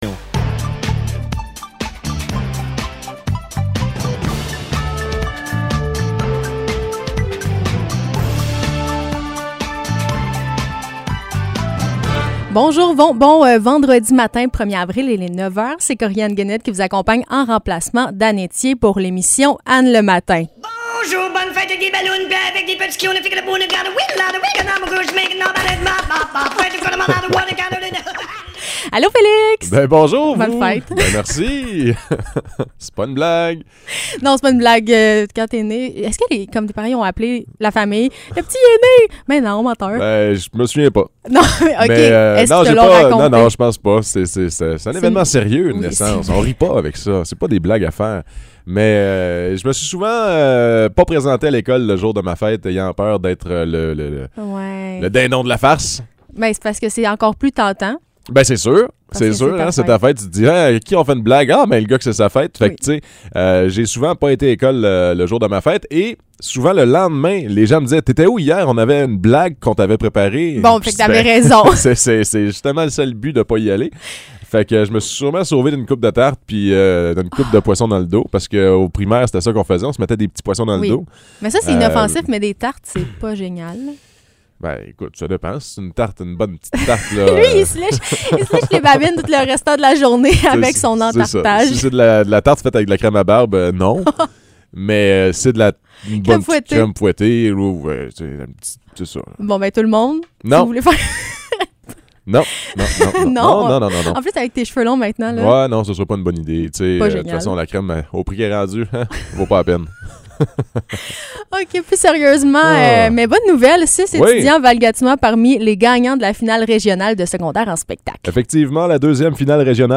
Nouvelles locales - 1er avril 2022 - 9 h